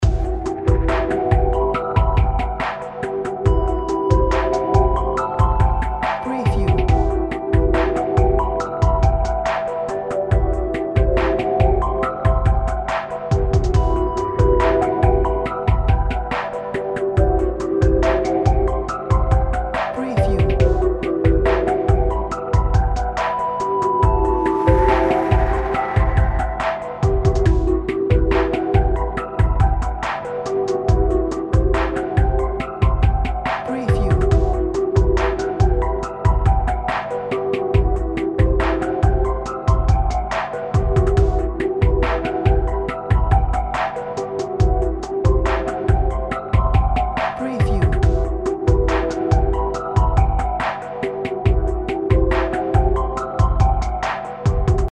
Atmospheric, unkown, mysterious and spacey music loops. 2 versions included.